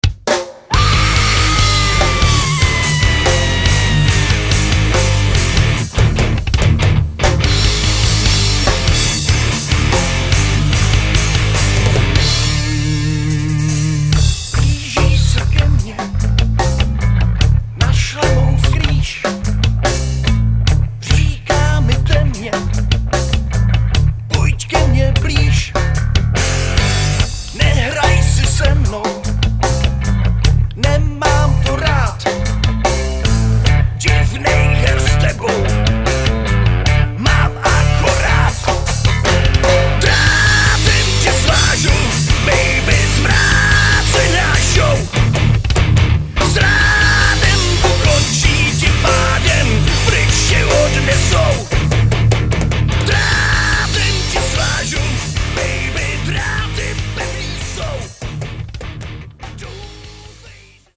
zpěv
kytara
bicí